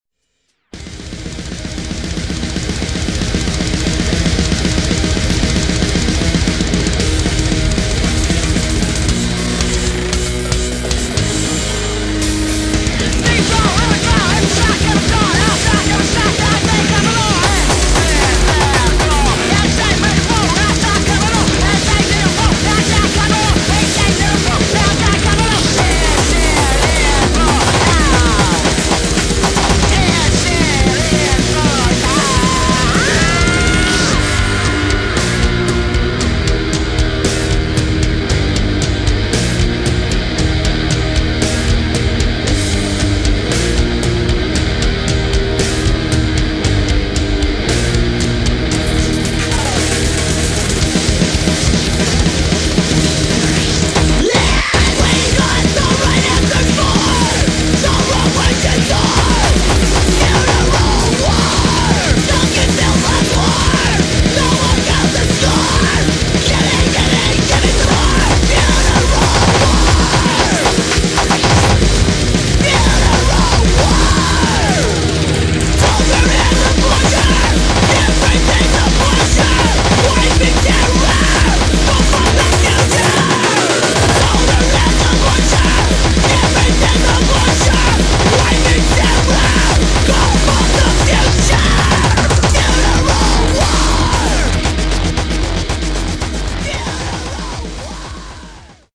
[ HARCORE ]
ブレイクコア/ハードコア/メタル